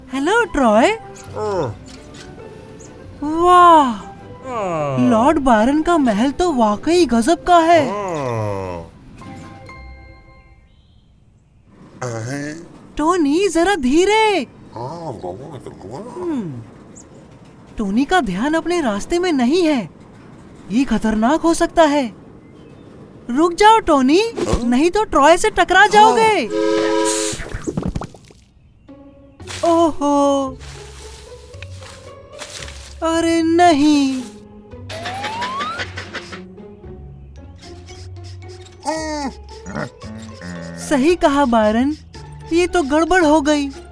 动漫角色【少年配音】